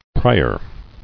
[pri·er]